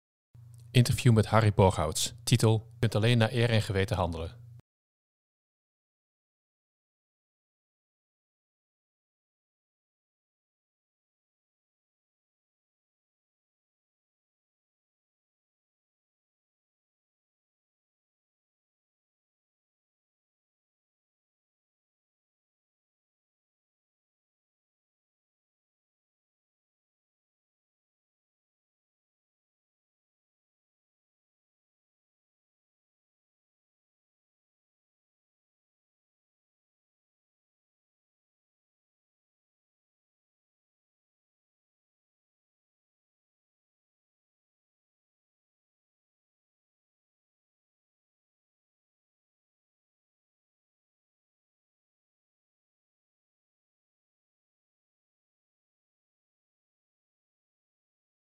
Interview met Harry Borghouts